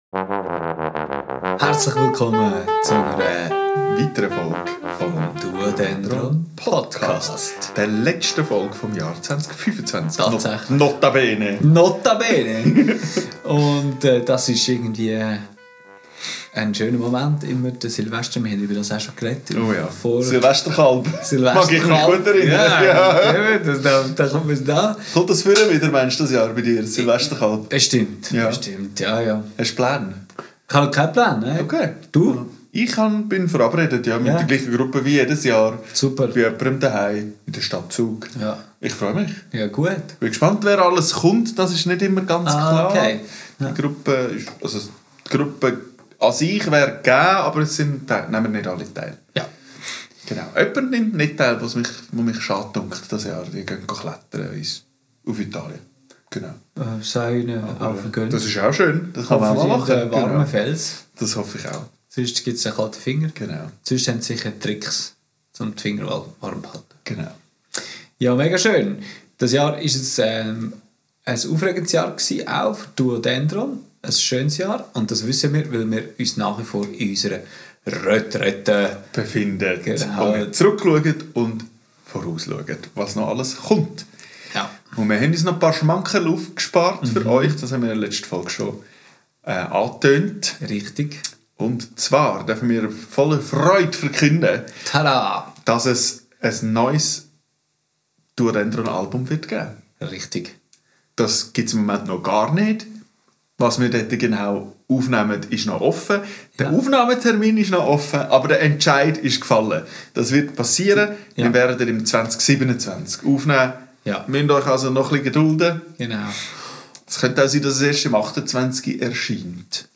Aufgenommen am 04.12.2025 in Rovio.